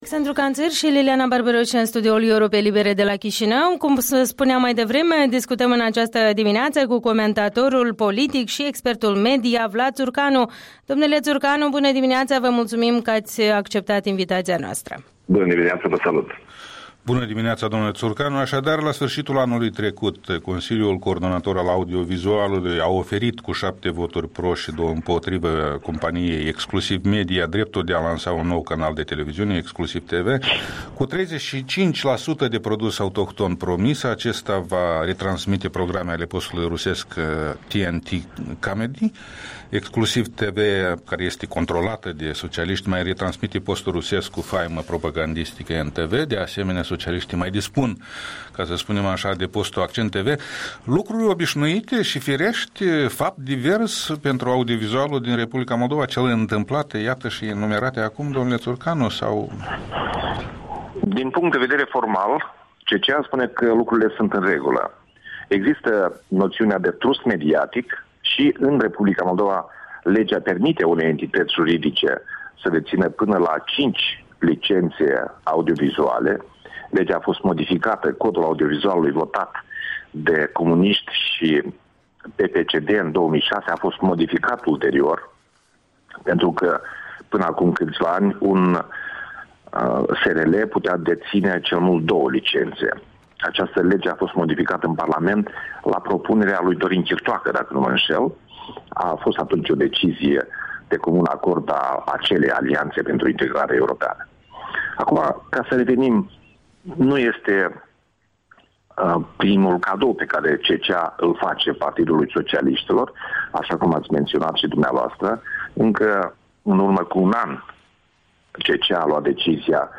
Interviul dimineții cu un expert media.